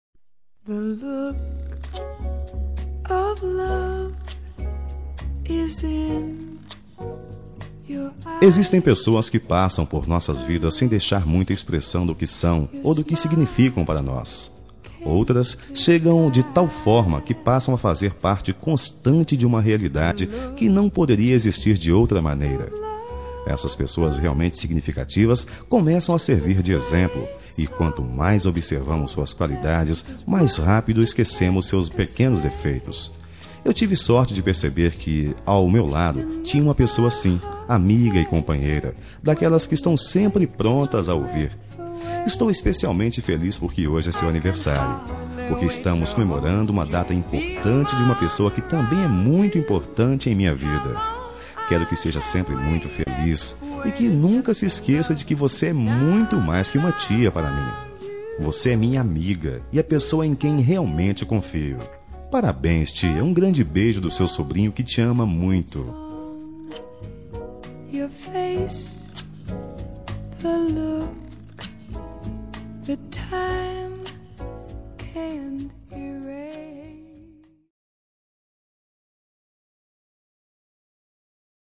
Telemensagem Aniversário de Tia – Voz Masculina – Cód: 2013